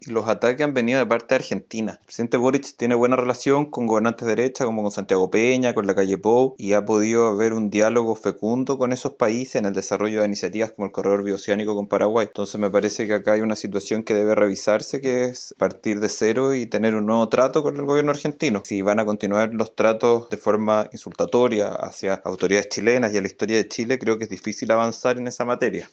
El diputado socialista e integrante de la comisión de Relaciones Exteriores, Tomás De Rementeria, planteó que se debe mejorar el trato que recibe nuestro país de parte de las autoridades trasandinas y “partir de cero”.